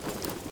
tac_gear_24.ogg